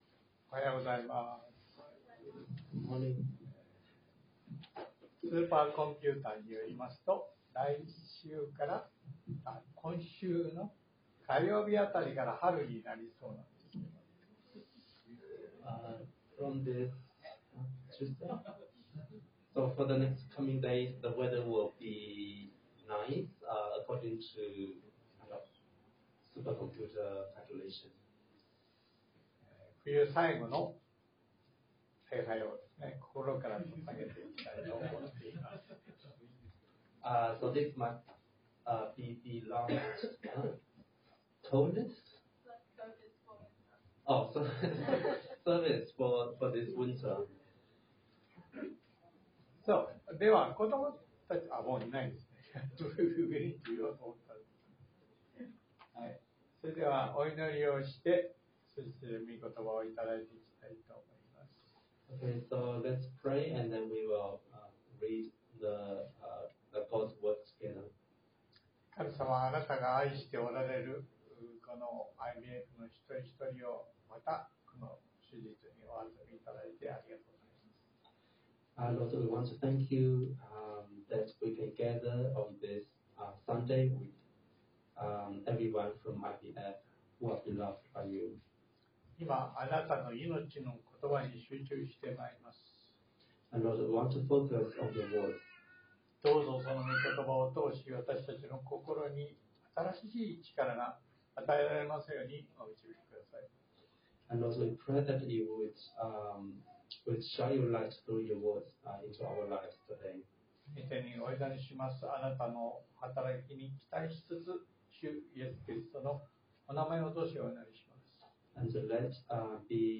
（日曜礼拝録音） 【iPhoneで聞けない方はiOSのアップデートをして下さい】 ➀きょうの聖書個所には、使徒パウロが、エルサレムに帰る直前、エペソの地方の教会の長老たちを呼び集めて、お別れの説教をしていった、その場面のことが書かれてあります。